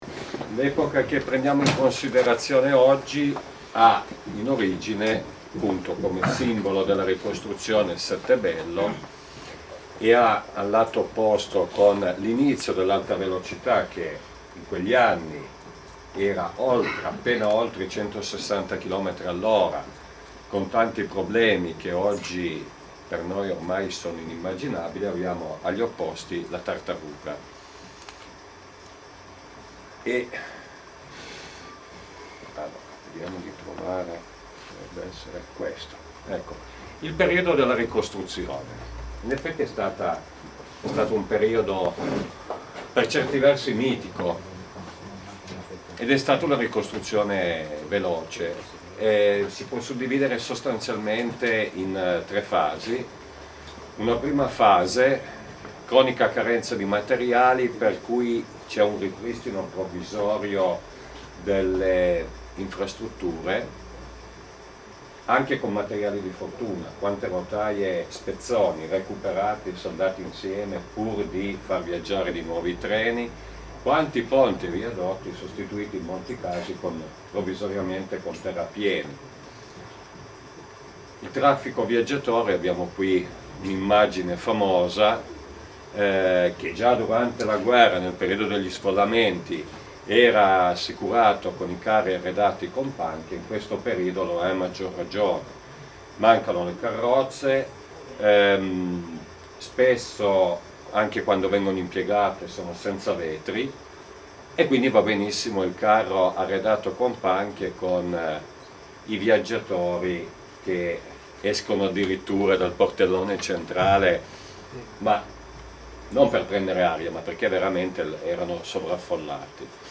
Sono disponibili le registrazioni audio delle conferenze del 14 dicembre 2012: